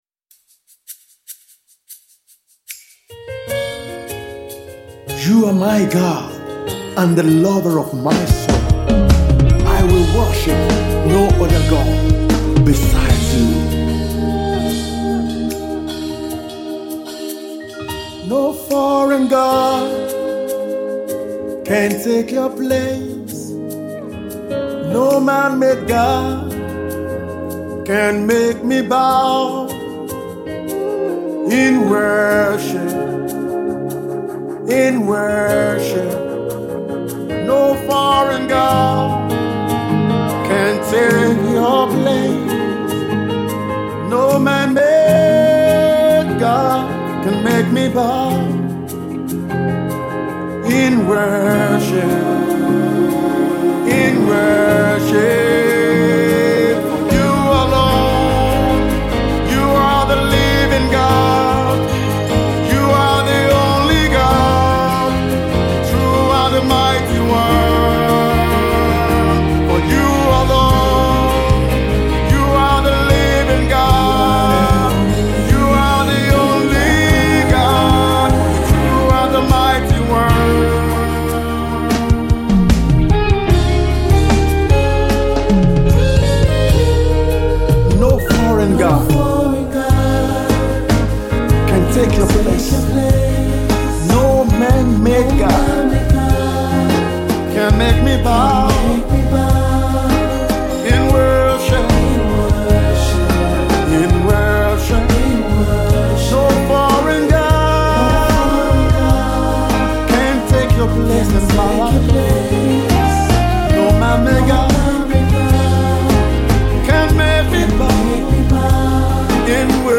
Song Writer and anointed praise/worship Leader